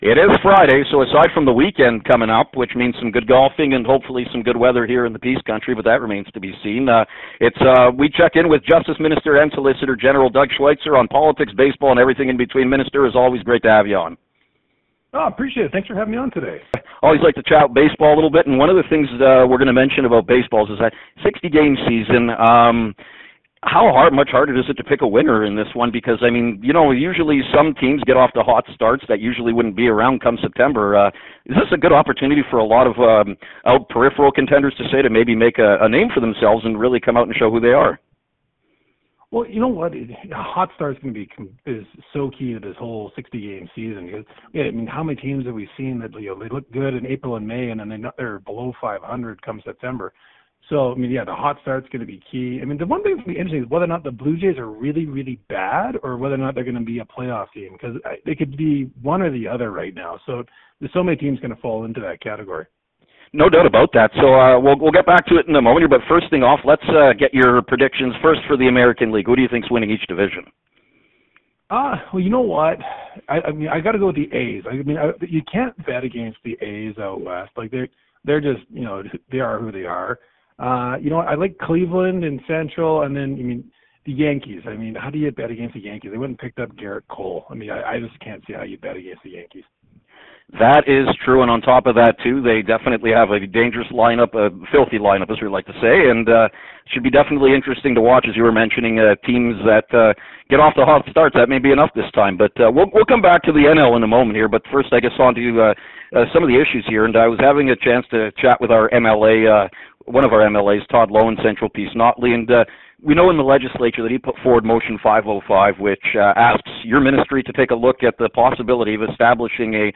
On Fridays, Justice Minister and Solicitor General, Doug Schweitzer joins us to talk about the latest news at the provincial level and weighs in on baseball as well.